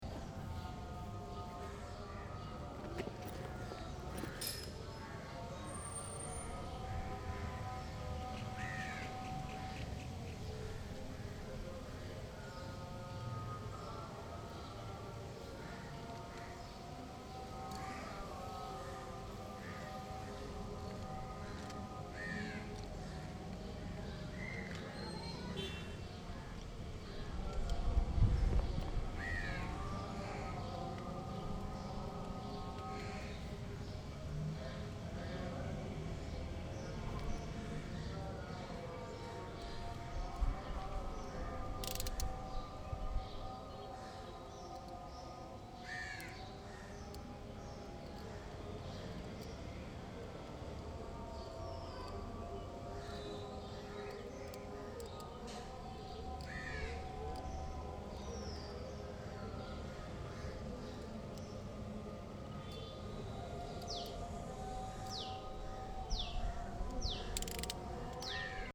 20/02/2014 15:00 Dans le sud de l'Inde, il y a beaucoup de catholiques.
Pendant que je dessine cette Sainte vierge et son enfant Jésus, le muezzin chante.